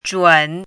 chinese-voice - 汉字语音库
zhun3.mp3